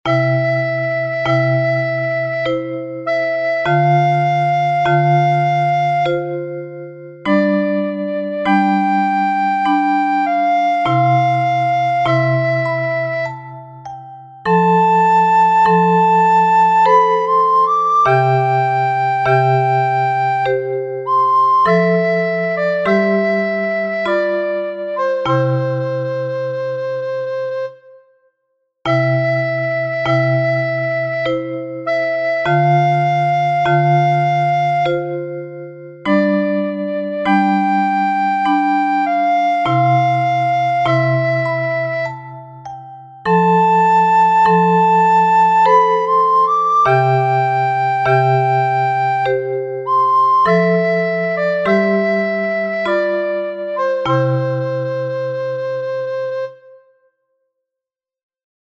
Un pouquiño máis lento:
Lascia_Chio_Pianga_lento.mp3